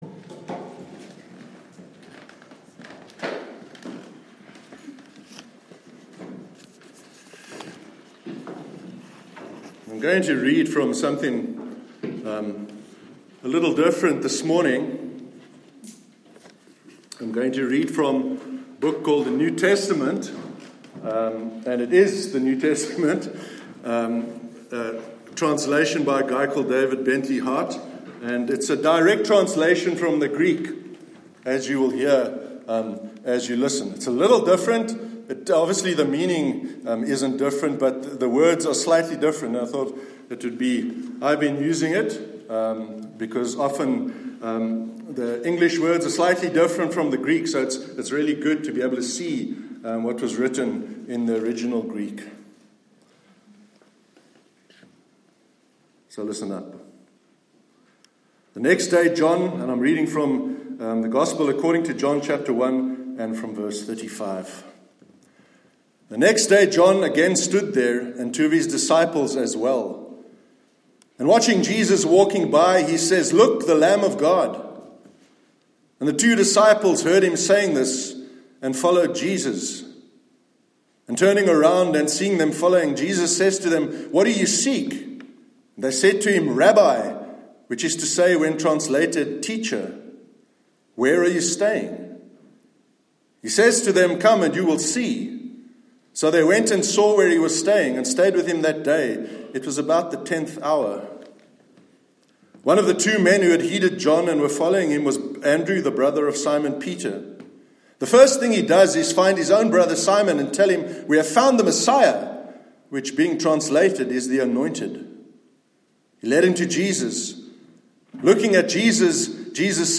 The Jewishness of John- Sermon 19th May 2019 – NEWHAVEN CHURCH